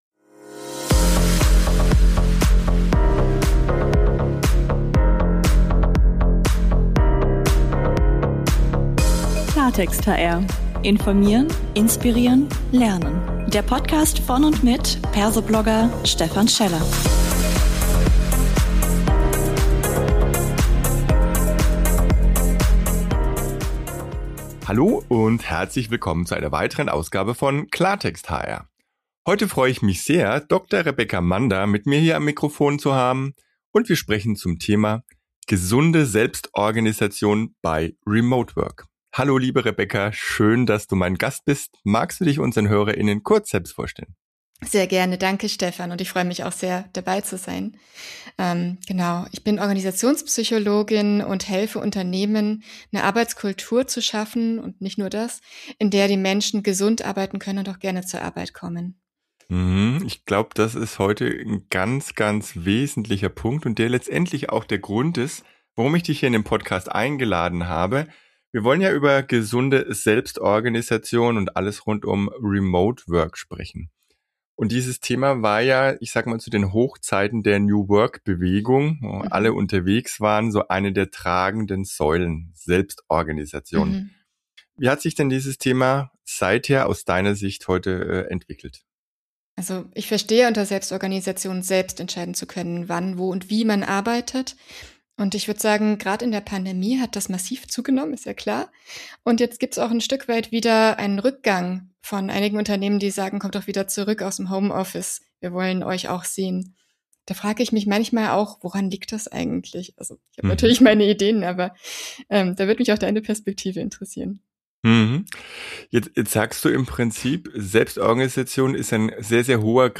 Ein spannender Talk als 15-Minuten-Impuls.